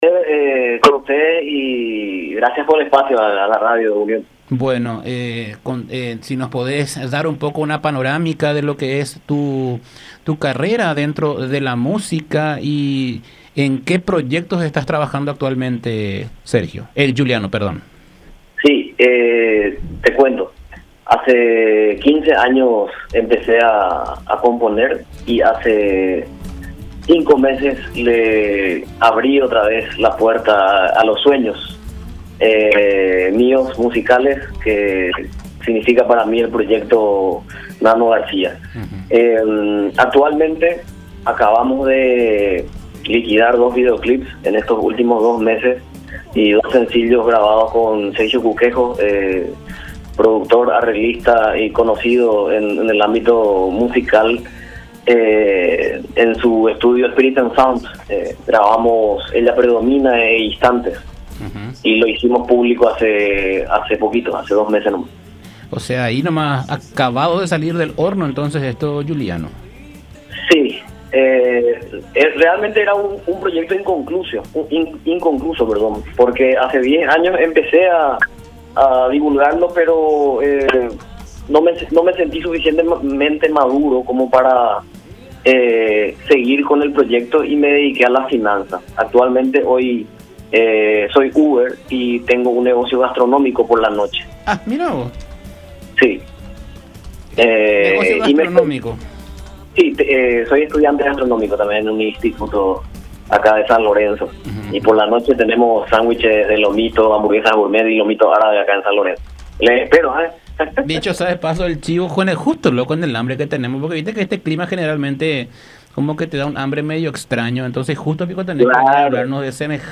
en diálogo con La Unión R800 AM.